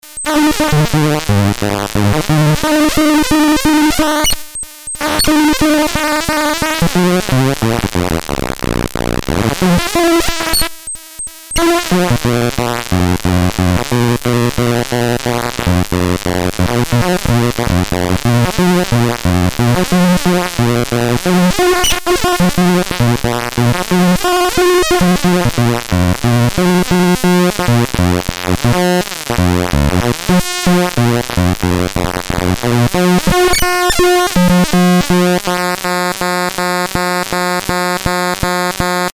edit VOICE this noisebox uses a dual 556 and it generates a single voice based on a pulse waveform with variable width.
demo hardware demo 2
REVIEW "cheap and fun to build! of course you to have to love those bleeps and other 8bits noises from the past."